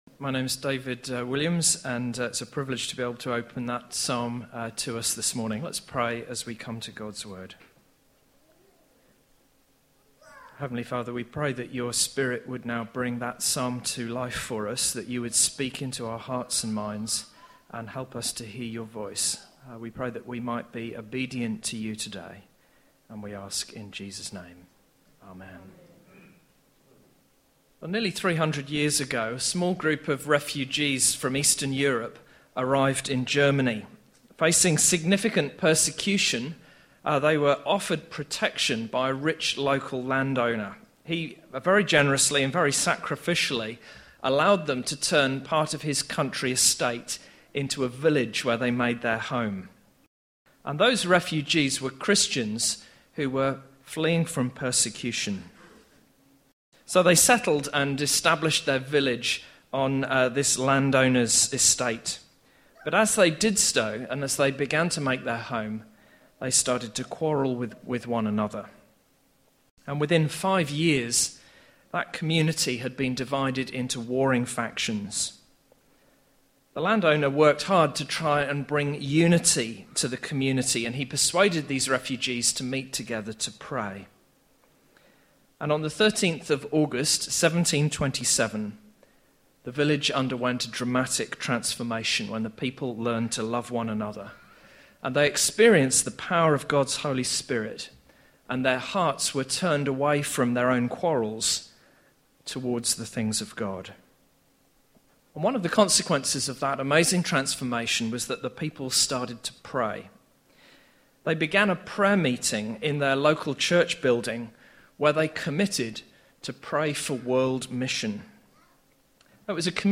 Bible Passage